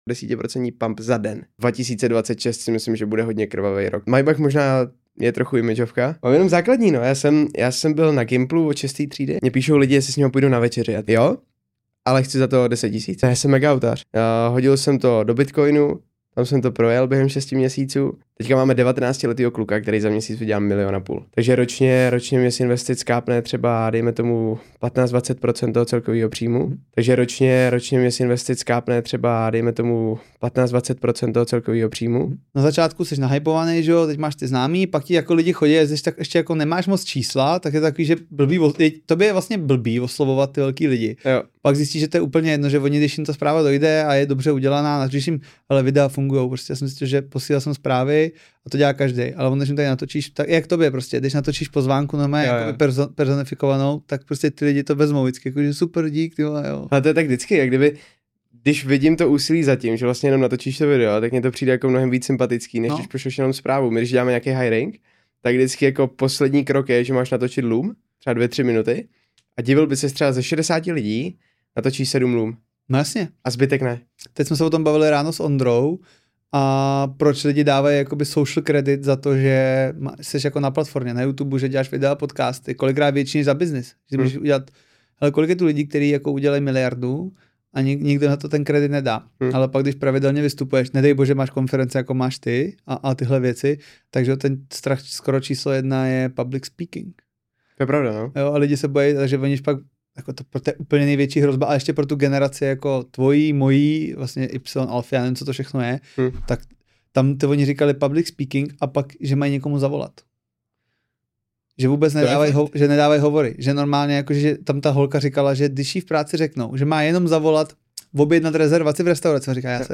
V rozhovoru otevřeně mluví o začátcích na vlastním kapitálu, české FTMO, mentoringu u světových jmen, výdělcích projektů, síle komunity i stavění osobního brandu. Autentický, inspirativní a surový pohled do světa tradingu, podnikání a mindsetu.